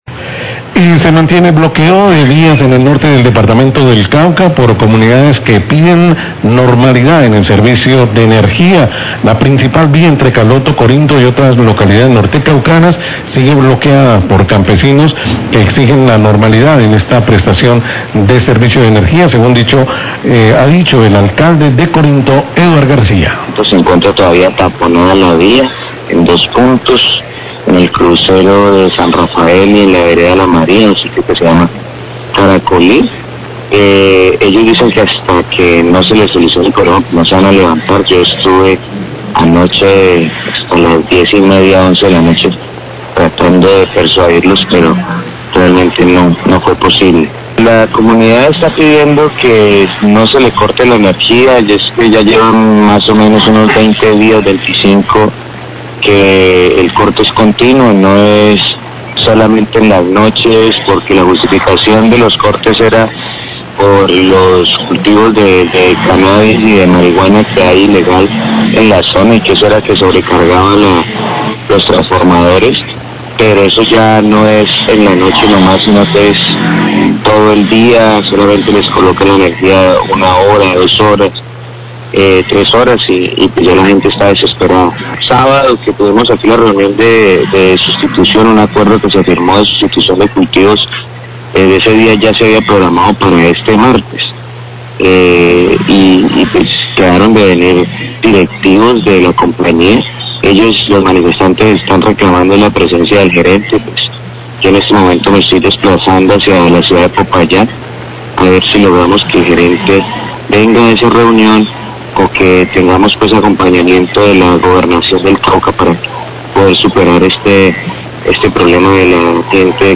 HABLA ALCALDE CORINTO SOBRE TAPONAMIENTO DE VÍAS POR COMUNIDADES QUE PIDEN NORMALIZACIÓN SERVICIO ENERGÍA
Radio
Declaraciones del Alcalde de Corinto Eduard García.